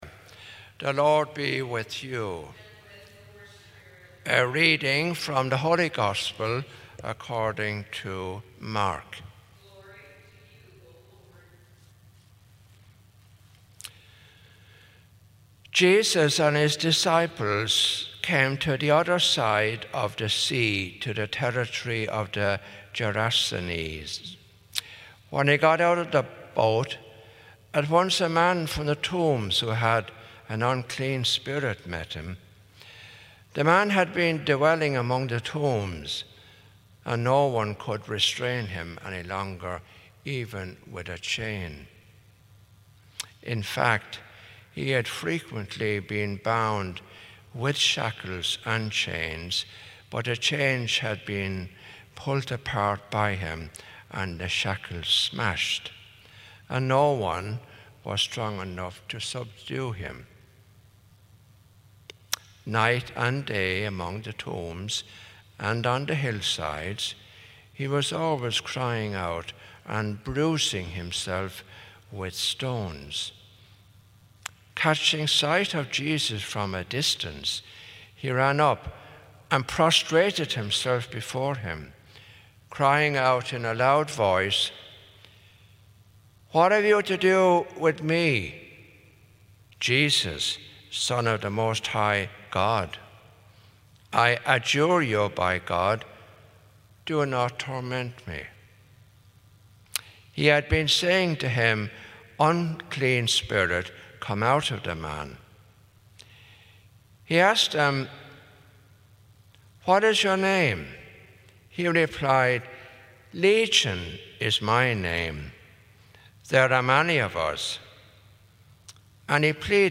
Gospel and Homily Podcasts
8:15 Mass Homilist